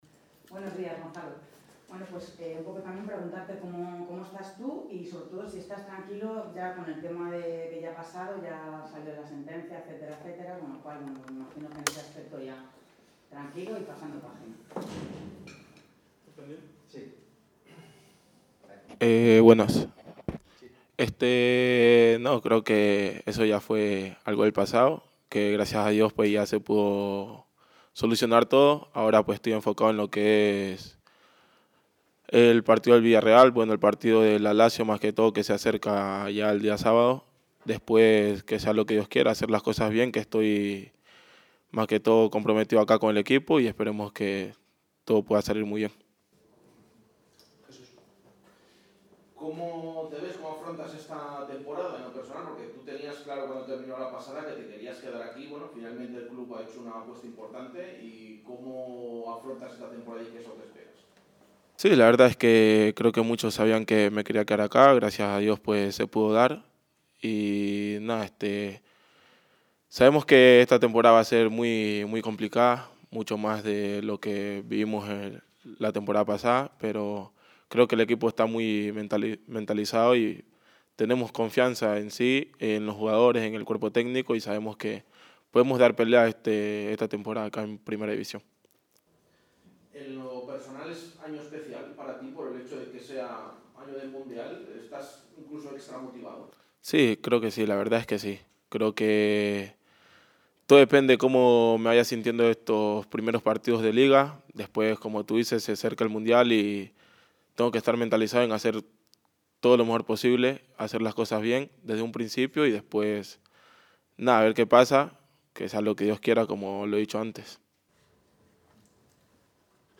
Gonzalo Plata ha comparecido en sala de prensa este jueves para valorar su fichaje por el Real Valladolid y responder sobre cómo afronta la temporada en Primera División, un curso "que sabemos que va a ser muy complicado, mucho más que el pasado" pero en el cual ve a un equipo "mentalizado y con confianza en jugadores y cuerpo técnico, sabemos que podemos dar pelea".